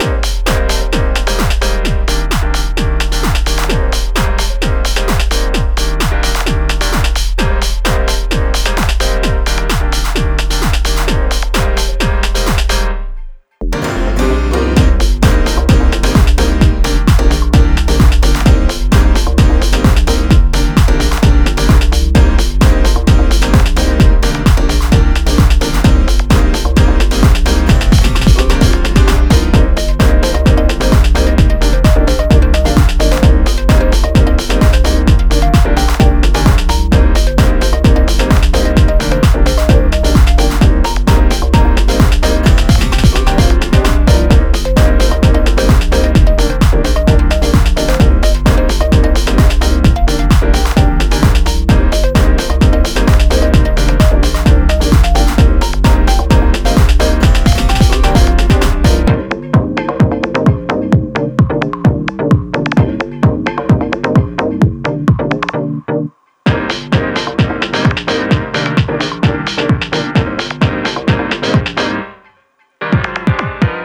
Soundtrack　 BPM:130　 garage
ループ OP エモ